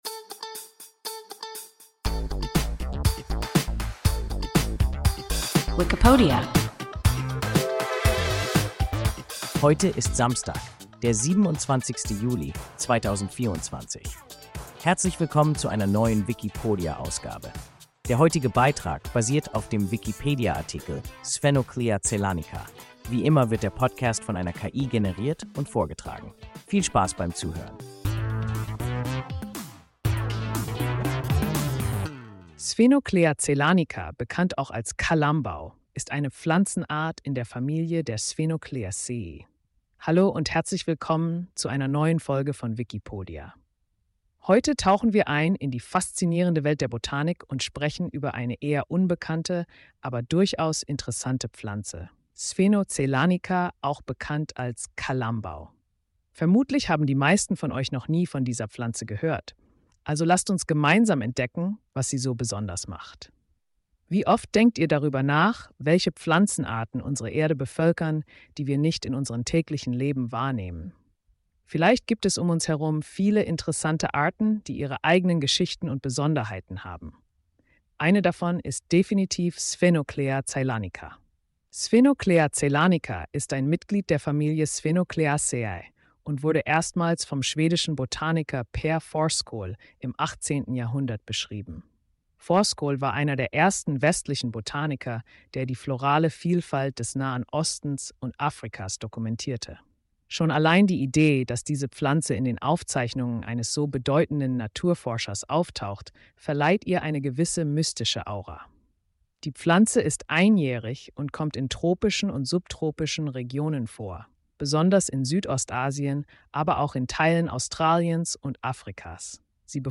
Sphenoclea zeylanica – WIKIPODIA – ein KI Podcast